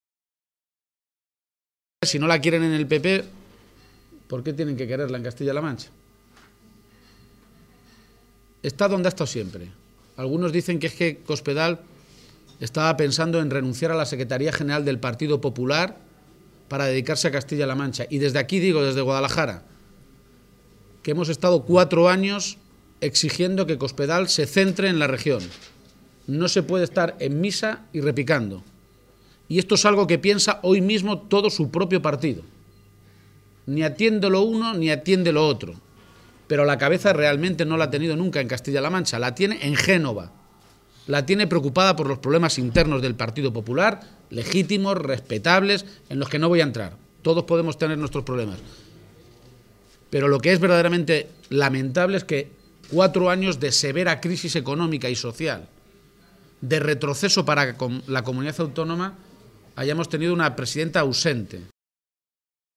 García-Page ha hecho esta afirmación en Guadalajara, donde ha mantenido un encuentro con medios de comunicación para avanzar las principales líneas de sus propuestas para Castilla-La Mancha, mientras Cospedal estaba en una reunión interna del PP convocada para analizar los desastrosos resultados de las elecciones andaluzas, “y convertida en el eje central de todos los problemas, también dentro de su partido”.